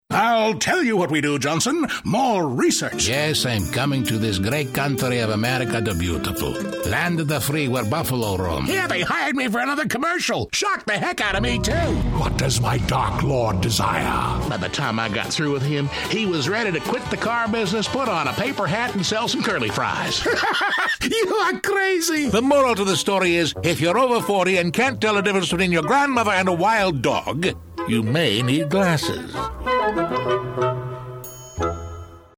He has a rich, warm voice, a natural style, and can also be very funny.
middle west
Sprechprobe: Sonstiges (Muttersprache):